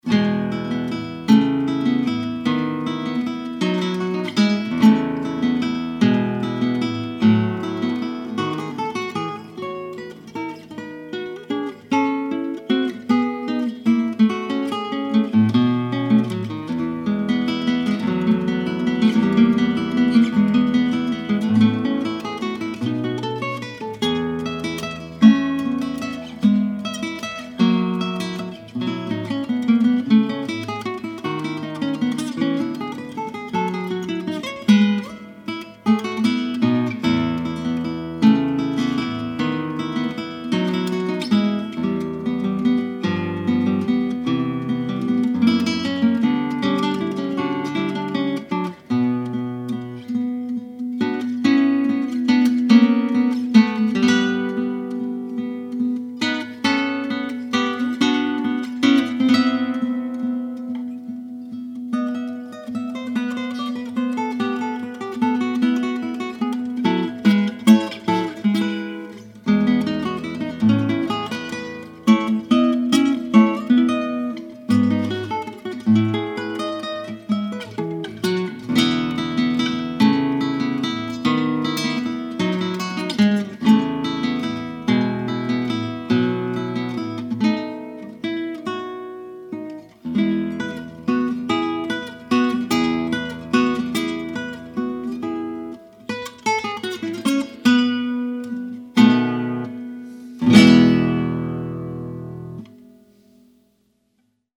It consists of a theme and seven variations.